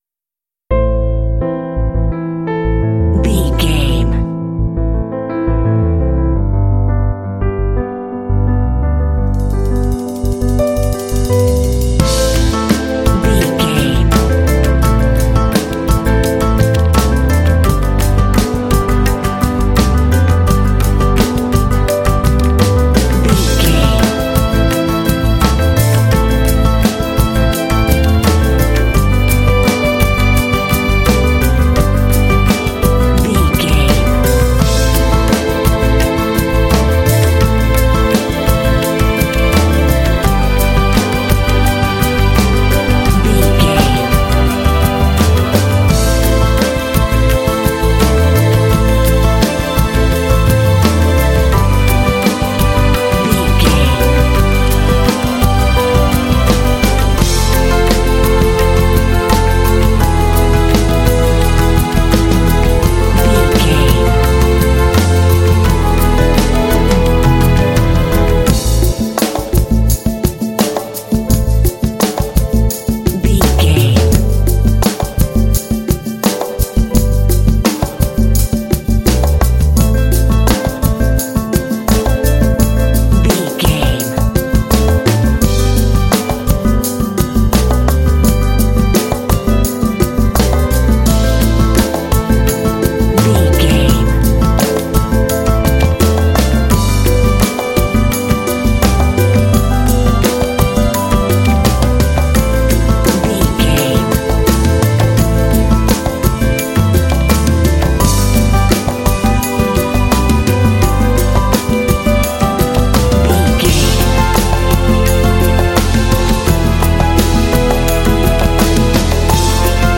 Aeolian/Minor
smooth
hopeful
dramatic
piano
strings
drums
congas
symphonic rock
cinematic